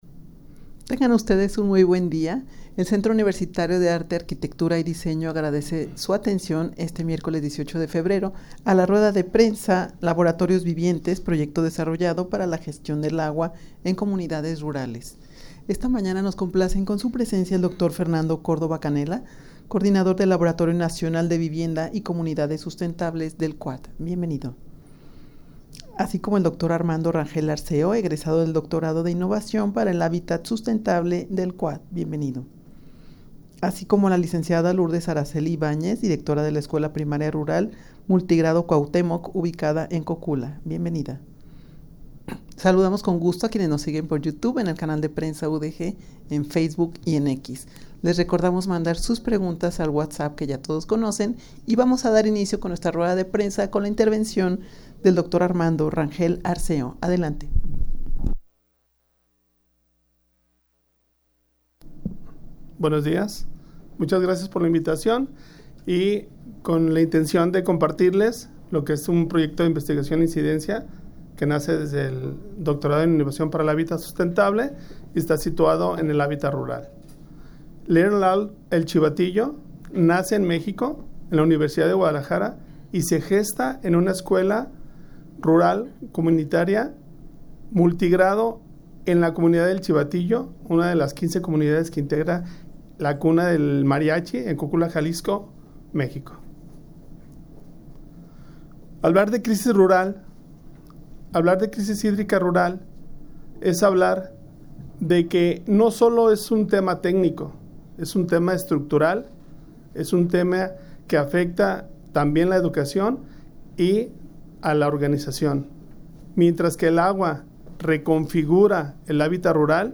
rueda-de-prensa-laboratorios-vivientes-proyecto-desarrollado-para-la-gestion-del-agua-en-comunidades-rurales.mp3